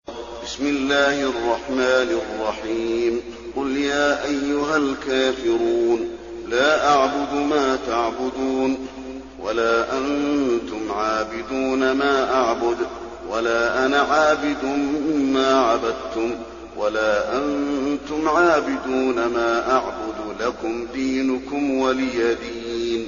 المكان: المسجد النبوي الكافرون